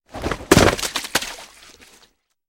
Звуки грязи
Шум тела, шлепнувшегося в грязь